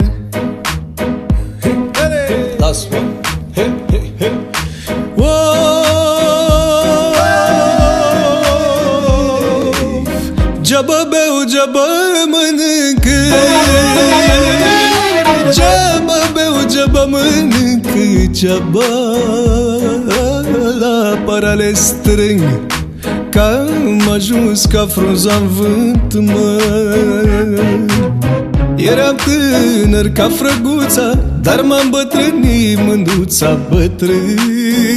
# J-pop